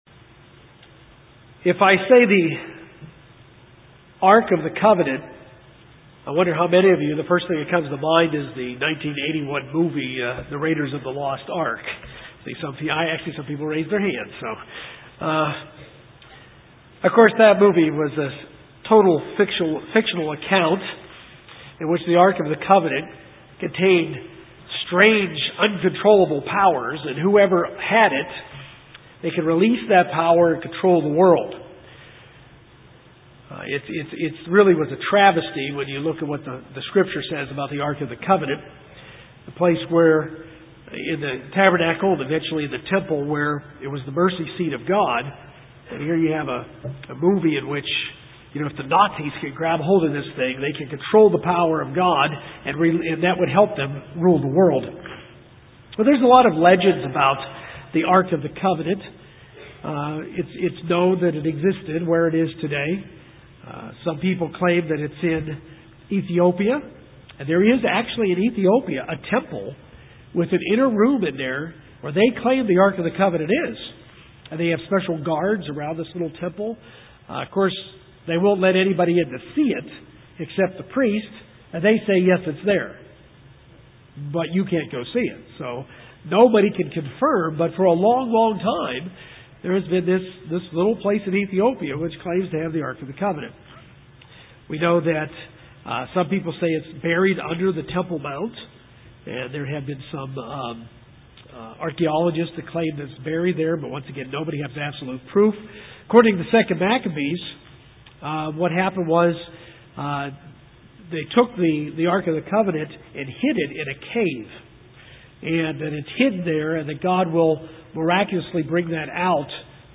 This message given on the Day of Atonement, relates the Ark of the Covenant Israel carried through the wilderness to how it relates to the new covenant and the intercessor that sit’s at the right hand of God reconciling us to the Father. How we observe the Day of Atonement today is much different than the way it was kept by Israel.